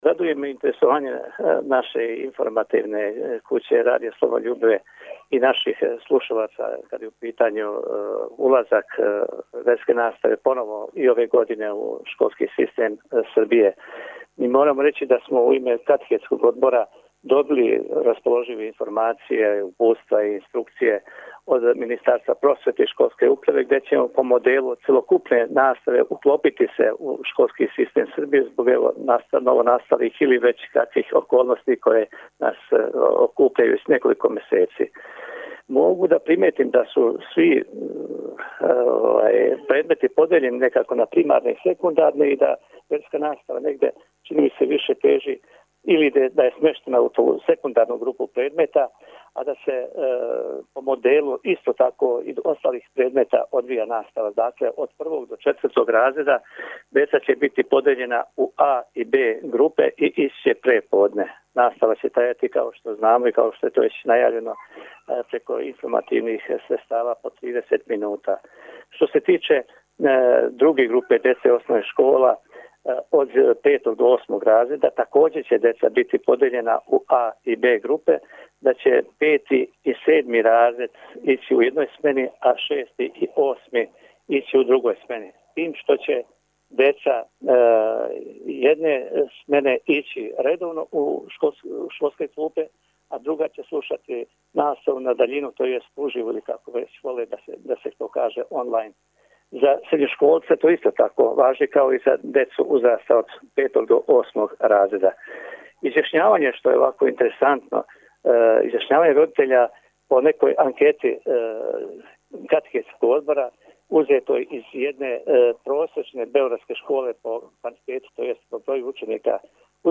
Звучни запис разговора Вероучитељи ће се као и њихове колеге педагози из свих осталих школских предмета понашати у складу са свим донесеним правилима из Министарства просвете Србије за наставу у нижим и вишим разредима основних школа и у средњим школама.